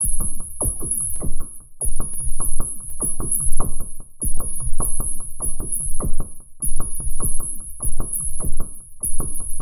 Abstract Rhythm 12.wav